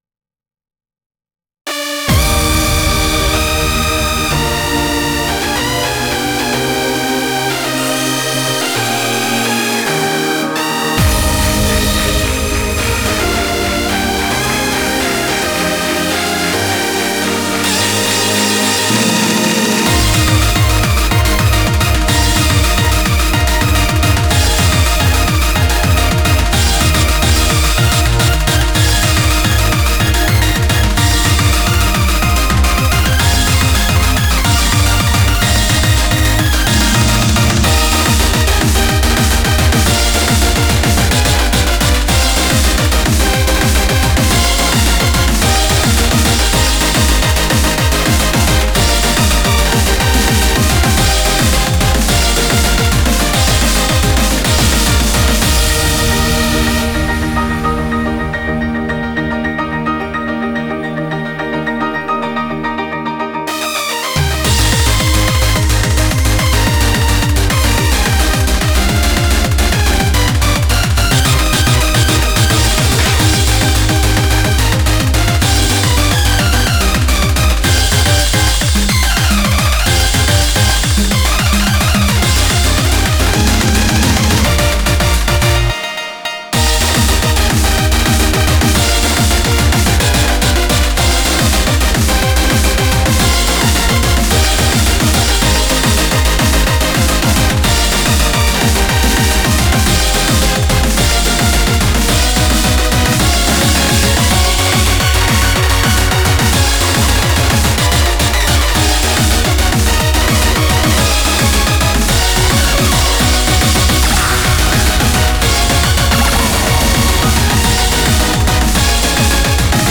BPM216